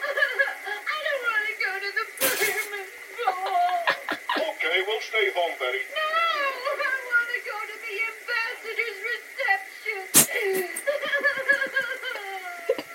Betty Rubble's sobbing/crying #2
From "Social Climbers", excerpted using Audacity, through the courtesy of Hanna-Barbera Enterprises, Inc.
betty-rubbles-sobbing-crying-2.mp3